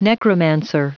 Prononciation du mot necromancer en anglais (fichier audio)
Prononciation du mot : necromancer